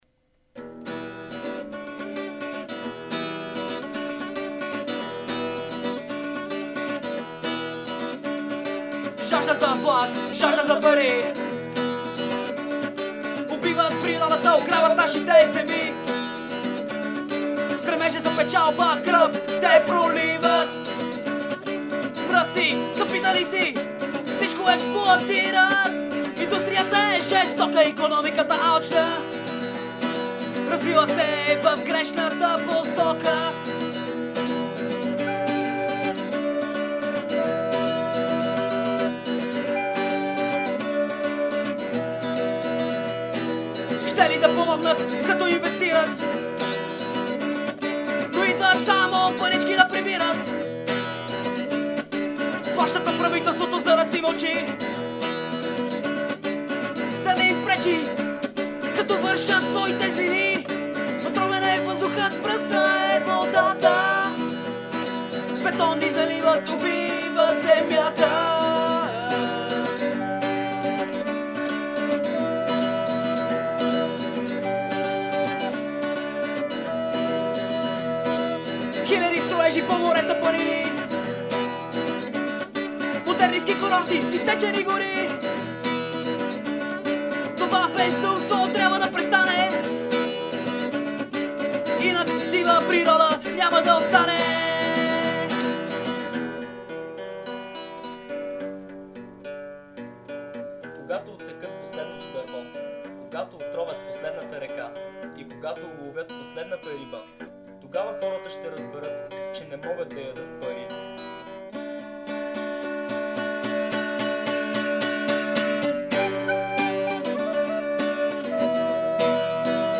vocal and guitar
flute and back vocals
marakas